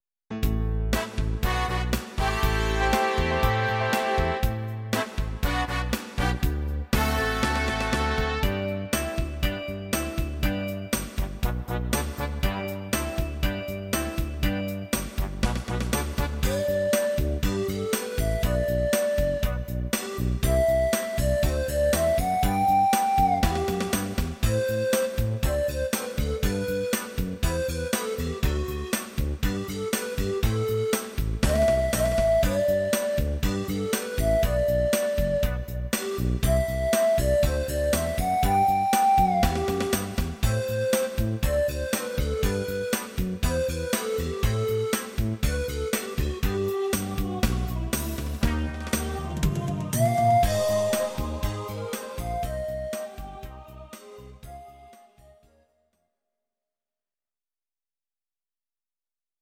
Audio Recordings based on Midi-files
Our Suggestions, Pop, German, 1970s